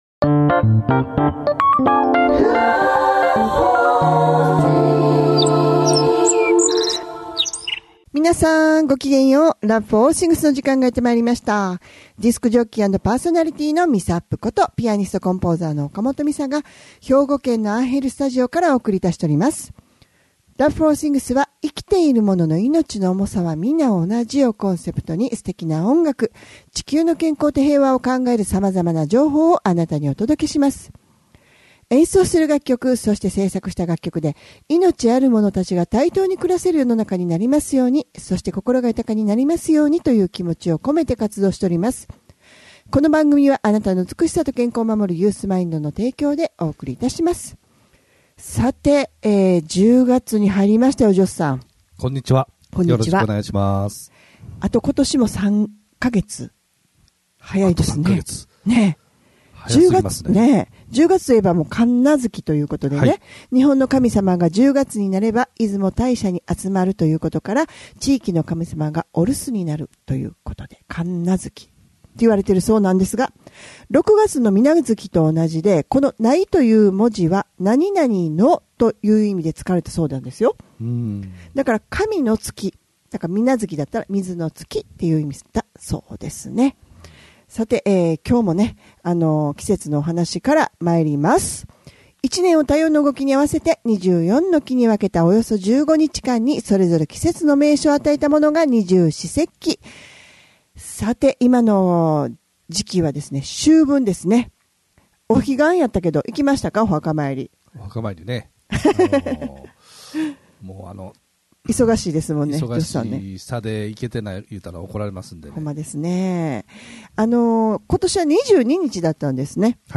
生きているものの命の重さは同じというコンセプトで音楽とおしゃべりでお送りする番組です♪